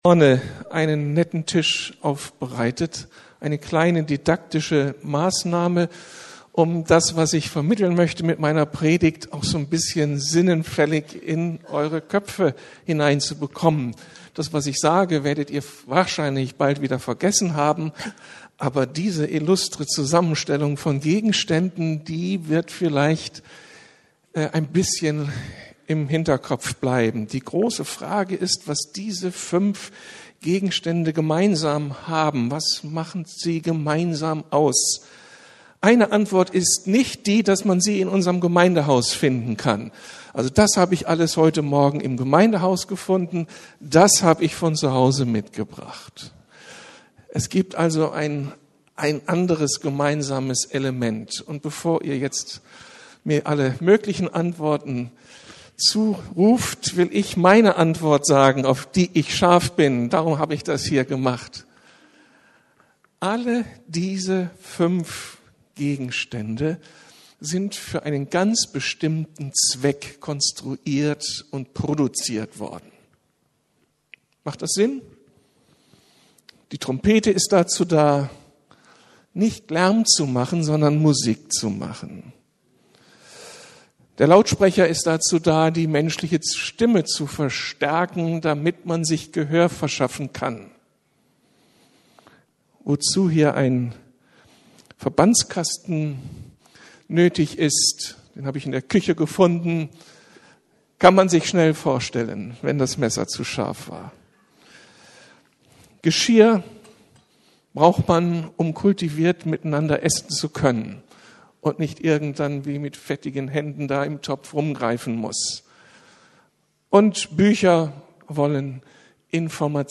Auf dass Gemeinde rund läuft! ~ Predigten der LUKAS GEMEINDE Podcast